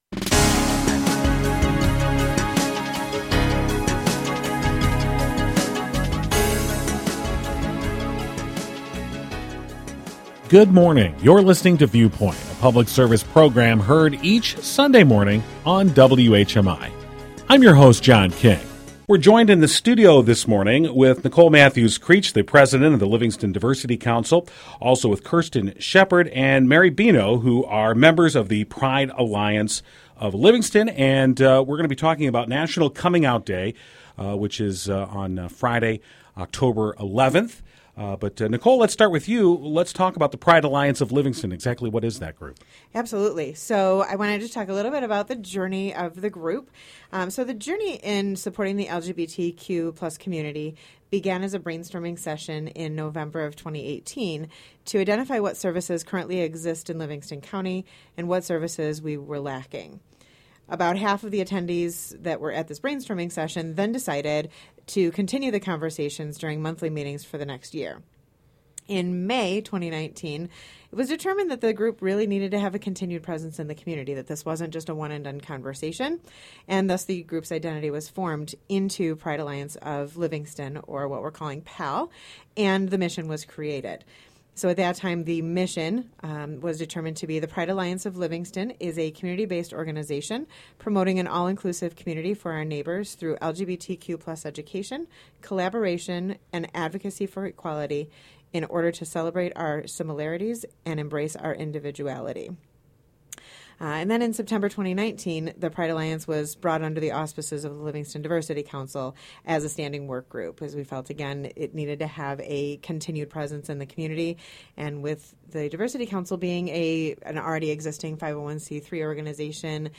Members of the Pride Alliance of Livingston were featured on the WHMI Viewpoint segment that aired on Sunday, October 6th with some education and awareness on how to support our LGBTQ+ community on this National Coming Out Day.